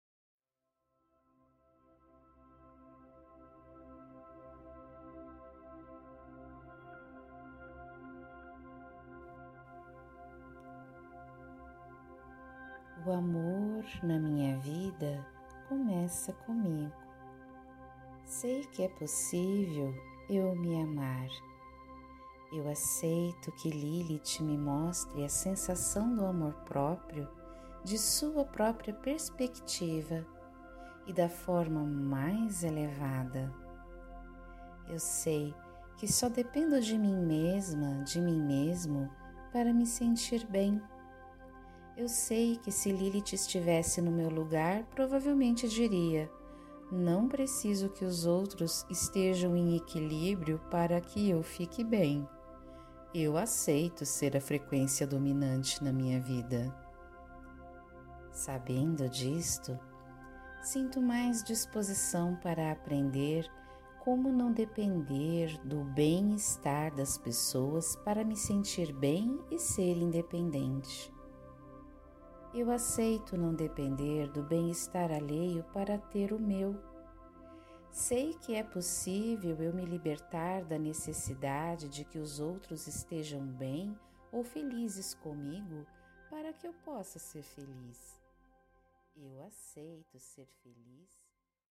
Esta meditação foi projetada para lhe transmitir e alinhar as crenças que Lilith tem sobre amor próprio, dentro de você.